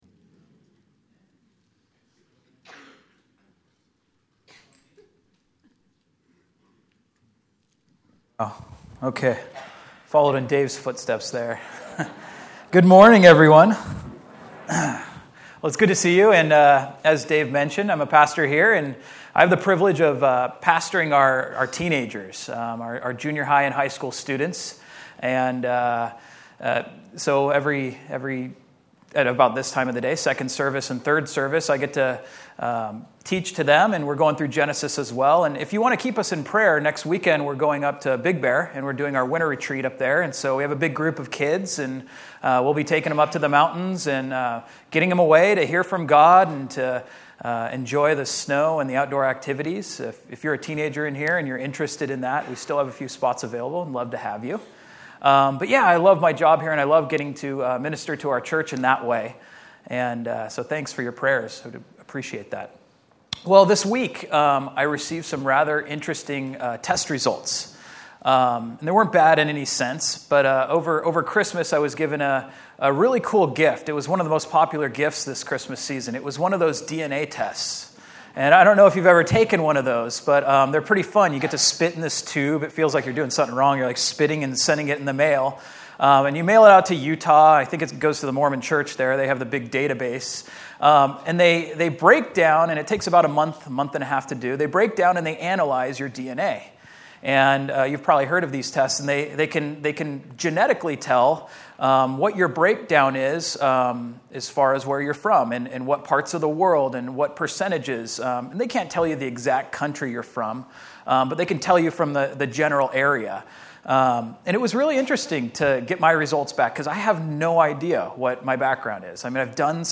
A message from the series "Miscellaneous Messages."